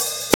Closed Hats
Hat (26).wav